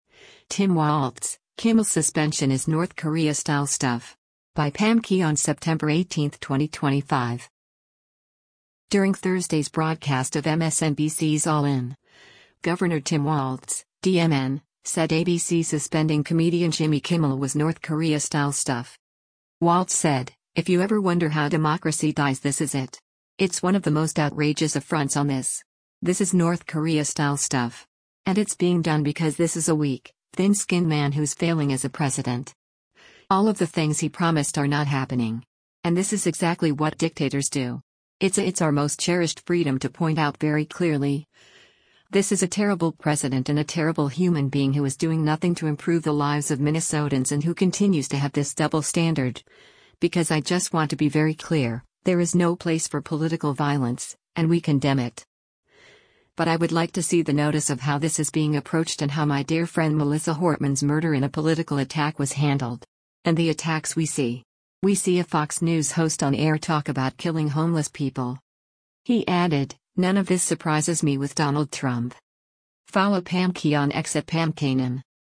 During Thursday’s broadcast of MSNBC’s “All In,” Gov. Tim Walz (D-MN) said ABC suspending comedian Jimmy Kimmel was “North Korea-style stuff.”